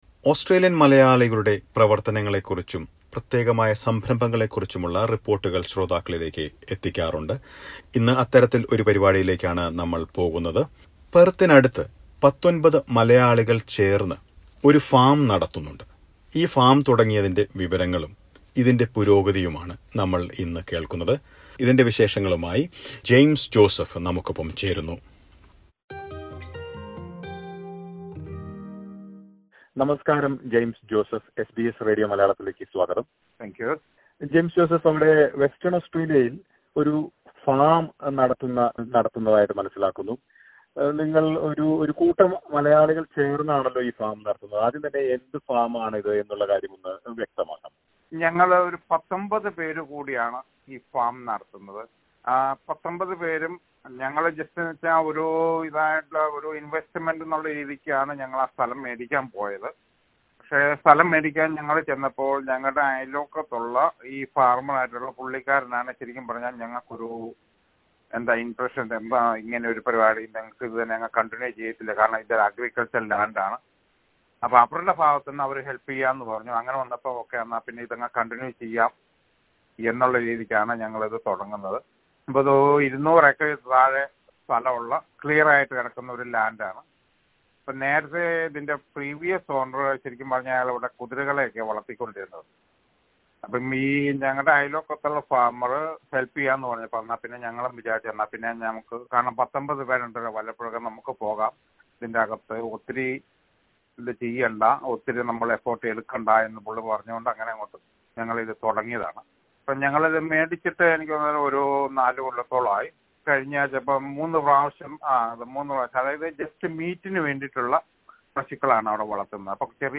Listen to a report on how a group of 19 Malayalees are running a cattle farm near Perth.